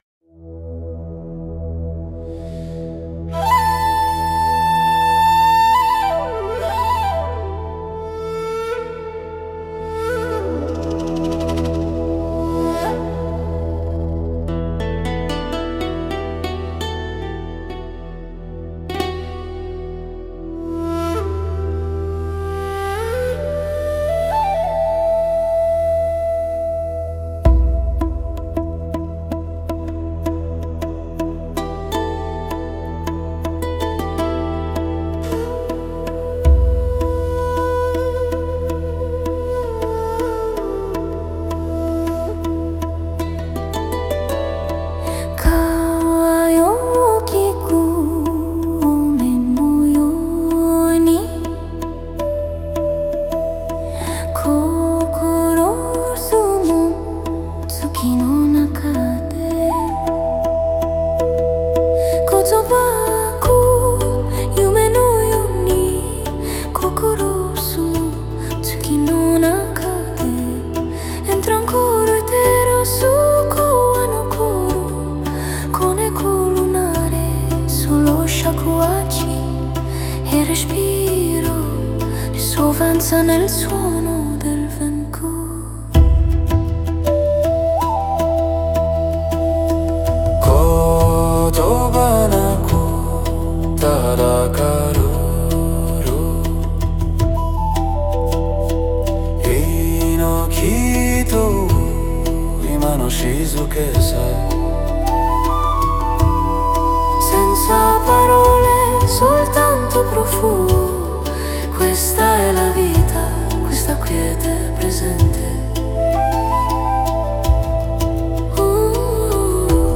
Un canto dedicato alla Via dell’Incenso (香道 – Kōdō)
Cantato in giapponese antico-moderno e composto su frequenza 528Hz, il pezzo unisce voce femminile eterea, shakuhachi e koto in un paesaggio sonoro che sembra sospeso tra sogno e meditazione.
La melodia segue il ritmo del respiro, come un rituale silenzioso:
Un ascolto meditativo
Frequenza 528Hz | Voce eterea, shakuhachi, koto, arpa giapponese